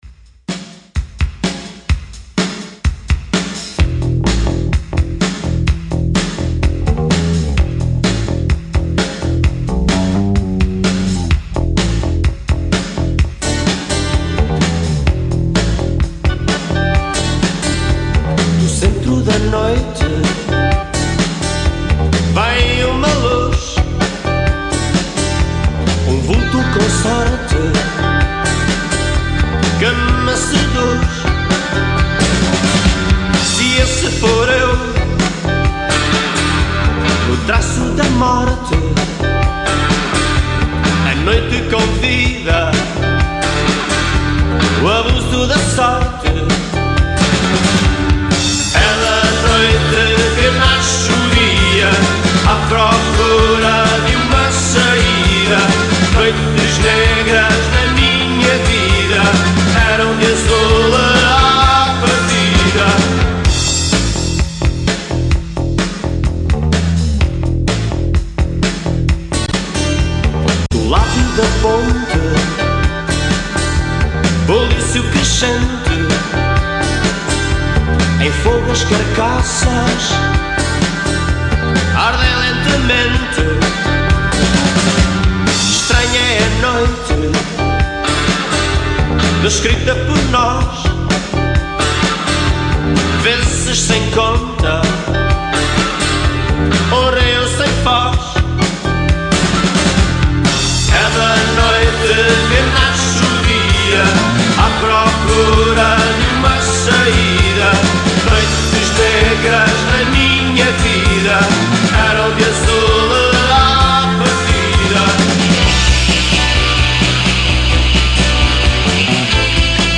On this show I’m going to tell a bit and play some songs of this amazing and iconic portuguese bands.